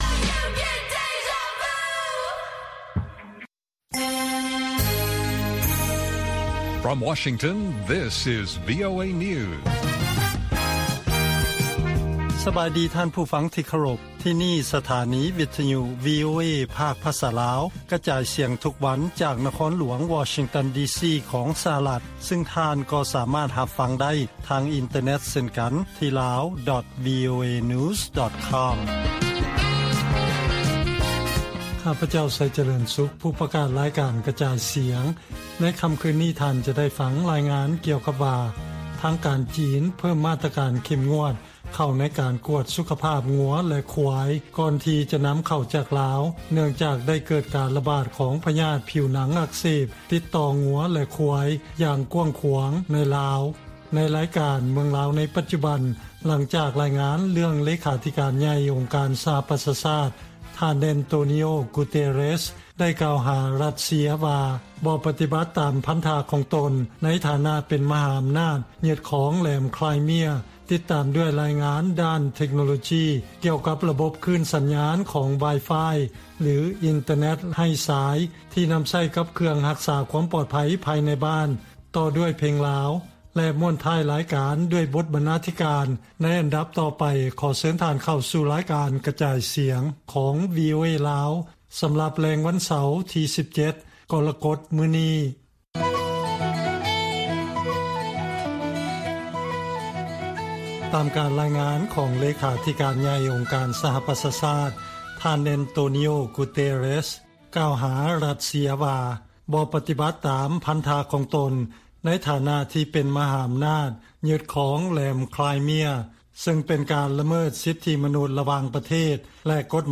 ວີໂອເອພາກພາສາລາວ ກະຈາຍສຽງທຸກໆວັນ. ຫົວຂໍ້ຂ່າວສໍາຄັນໃນມື້ນີ້ມີ: 1) ອົງການສະຫະປະຊາຊາດ ປະນາມຣັດເຊຍໃນການເຂົ້າຢຶດຄອງແຫລມໄຄຣເມຍຢ່າງຜິດກົດໝາຍ. 2) ຄື້ນຂອງສັນຍານອິນເຕີແນັດ ສາມາດກວດຈັບ ຜູ້ລ່ວງລໍ້າເຂົ້າໄປໃນອາຄານ ຫລື ເຮືອນໄດ້ແລະຂ່າວສໍາຄັນອື່ນໆອີກ.